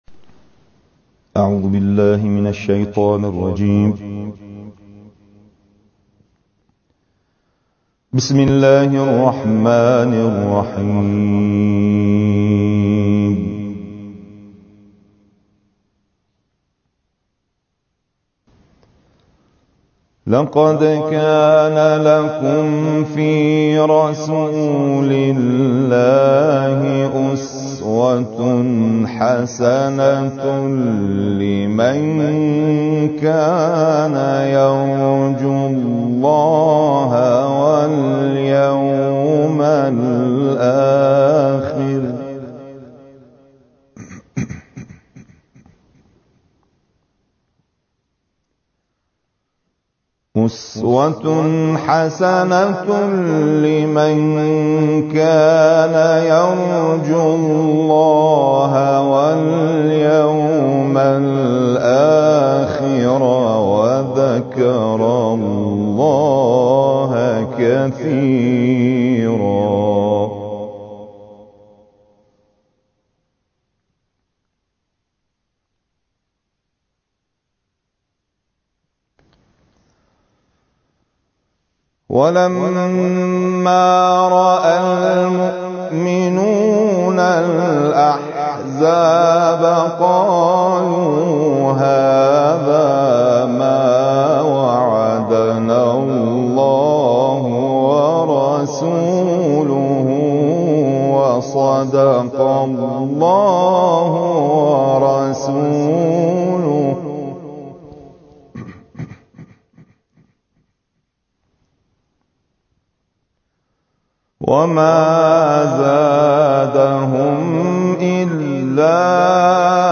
گروه فعالیت‌های قرآنی: پنج تلاوت قرآن کریم از پنج قاری بین‌المللی را بشنوید و تلاوت محبوب خود را در بخش نظرسنجی سایت ایکنا انتخاب کنید.